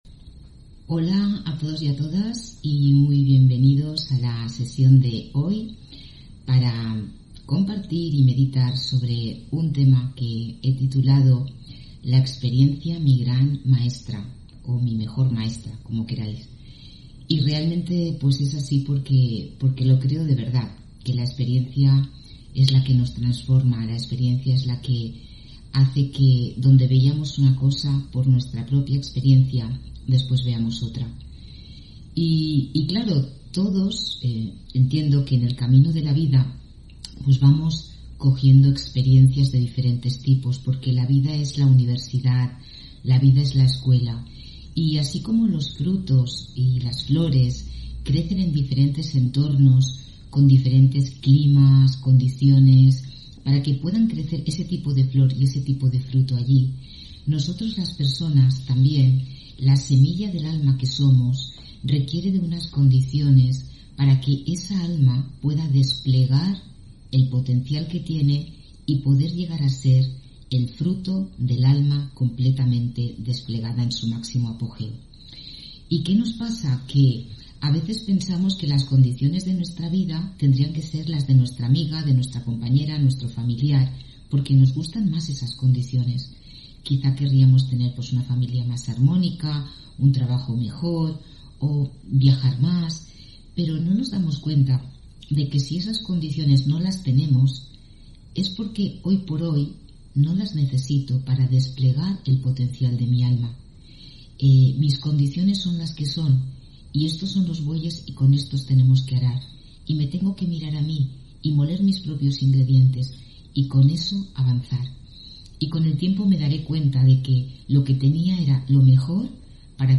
Meditación y conferencia: La experiencia, mi gran maestra (4 Diciembre 2021)